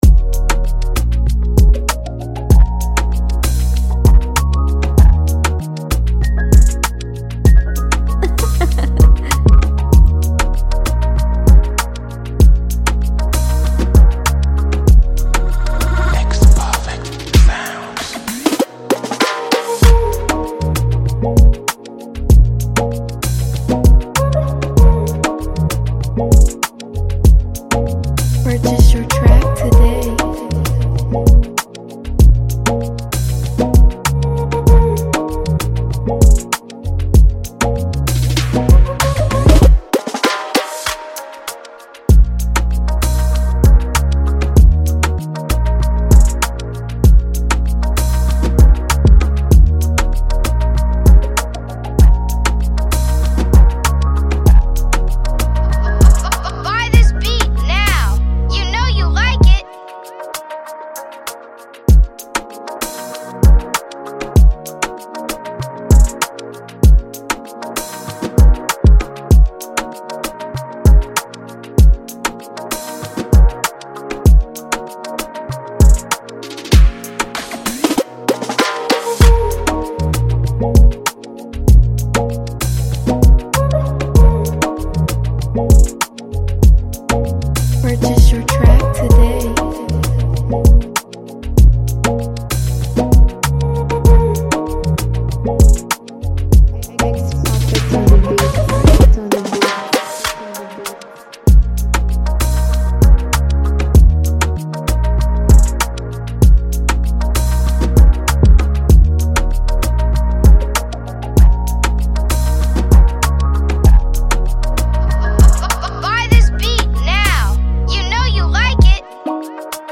• 🎵 Smooth and lively instrumental vibe
Clean, professional production